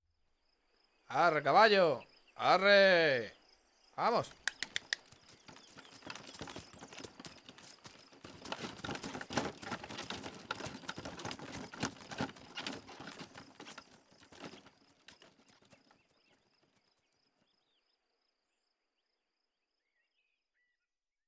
Grito a un caballo para que ande: Arre
Sonidos: Rural